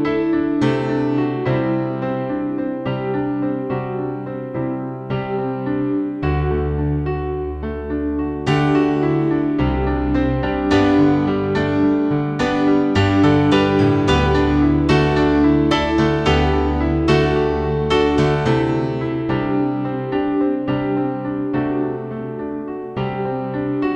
Live Pop (2000s) 4:23 Buy £1.50